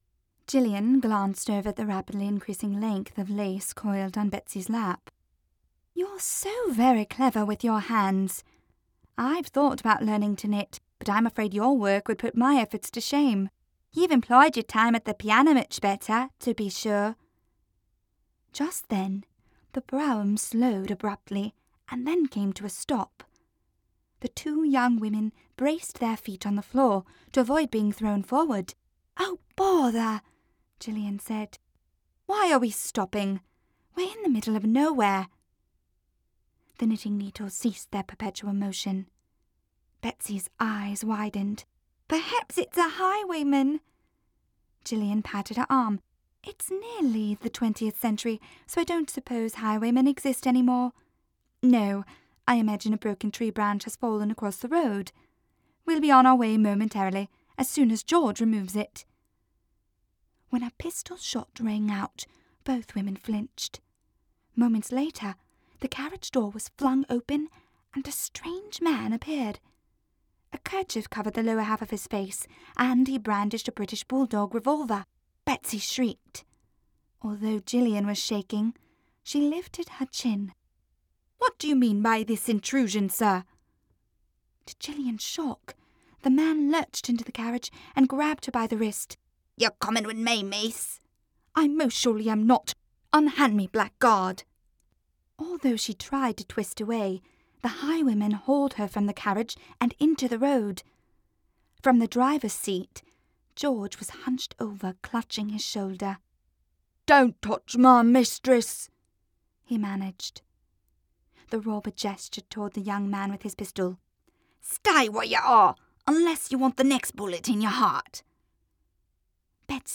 The Ice Captain’s Daughter is also available as an audiobook HERE